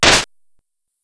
Damage.ogg